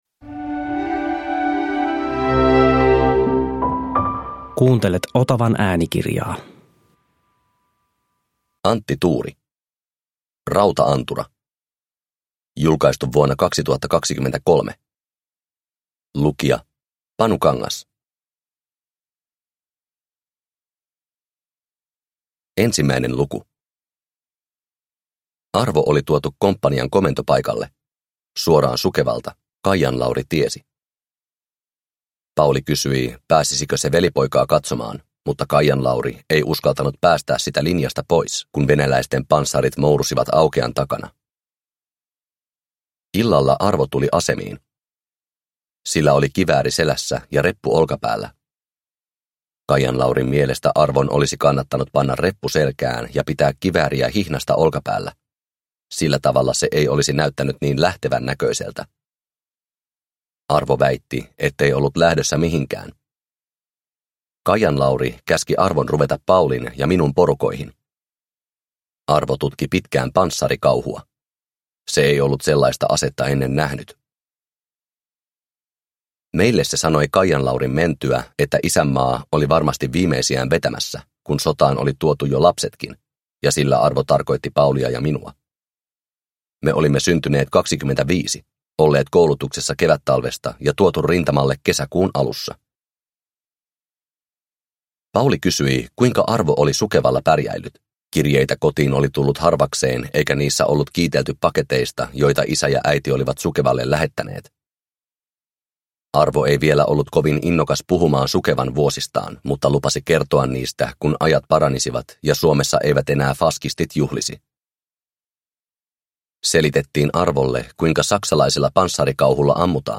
Rauta-antura – Ljudbok – Laddas ner